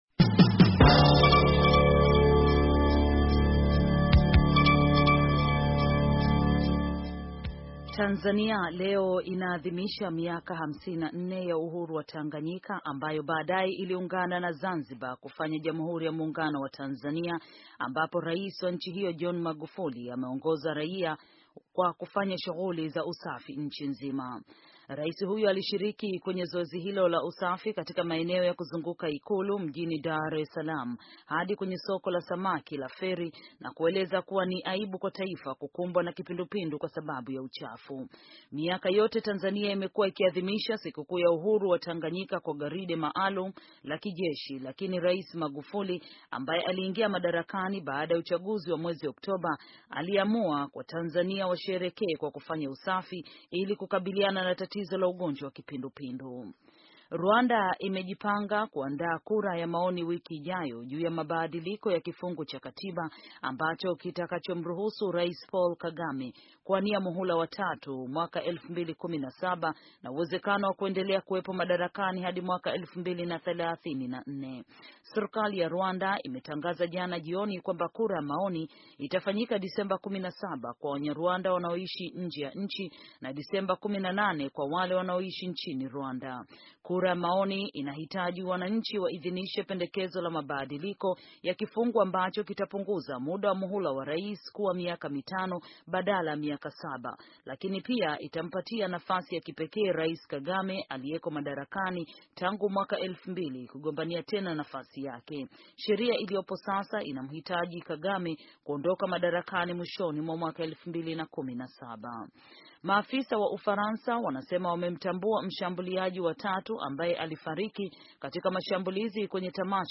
Taarifa ya habari - 6:06